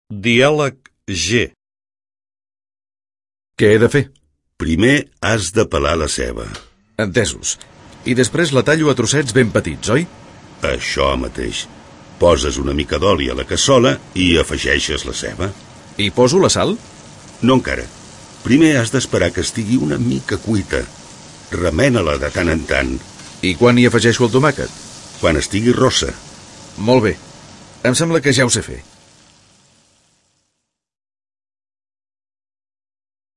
Diàleg G
Dialeg-G-el-menjar.mp3